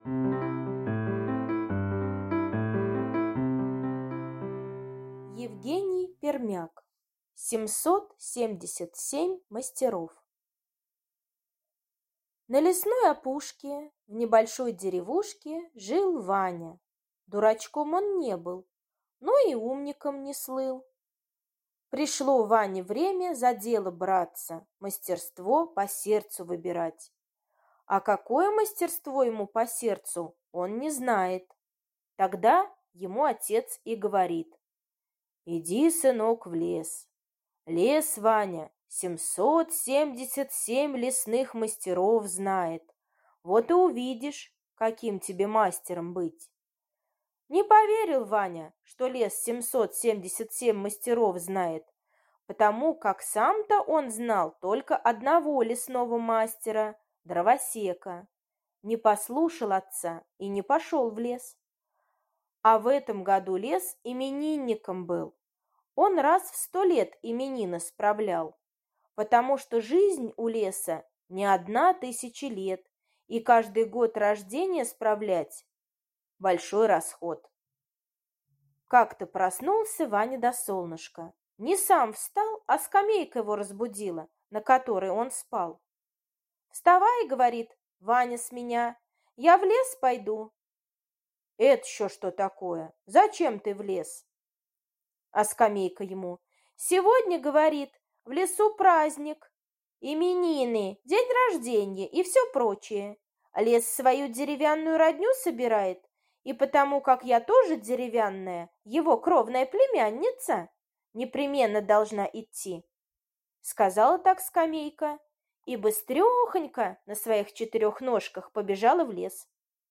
Аудиосказка «Семьсот семьдесят семь мастеров»